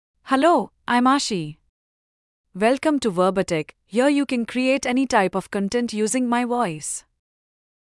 FemaleEnglish (India)
Aashi is a female AI voice for English (India).
Voice sample
Female
Aashi delivers clear pronunciation with authentic India English intonation, making your content sound professionally produced.